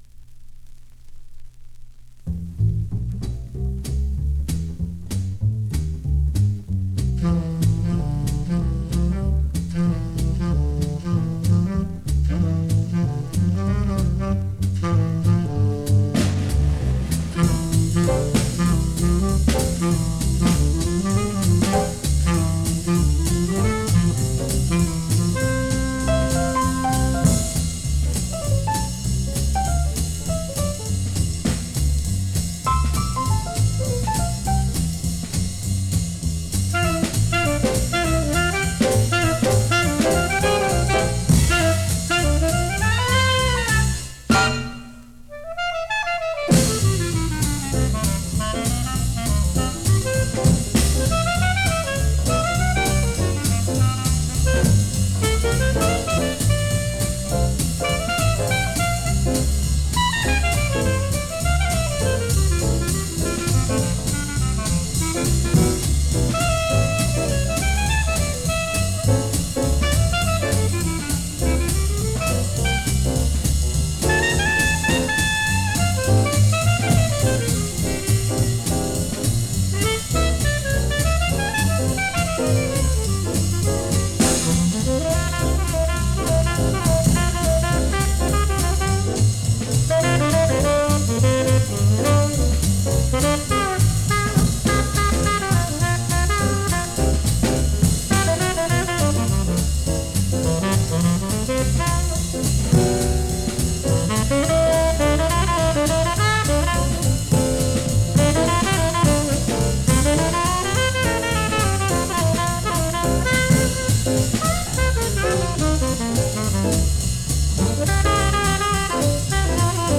Recorded: 1960 in Sydney, Australia
Clarinet
Tenor Sax
Piano
Bass
Drums
everyone gets a chance to solo.